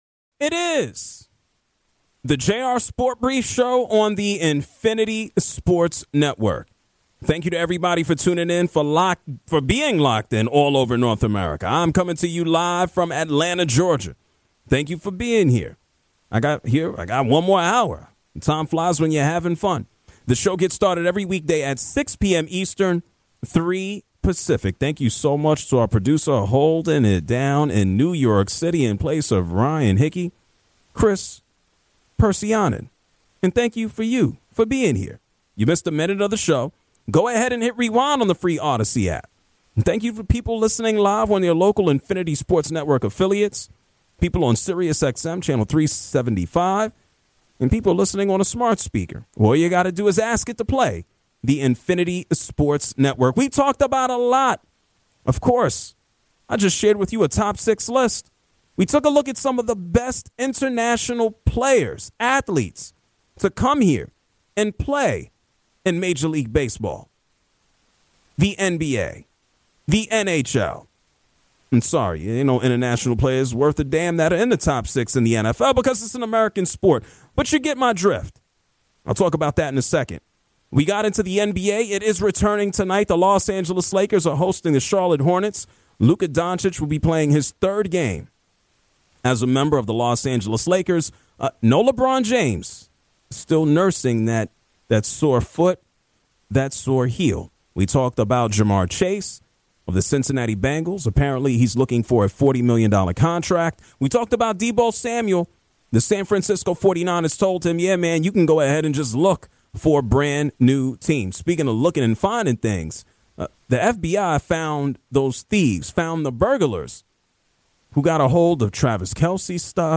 He also takes your calls and runs you through this day in sports history!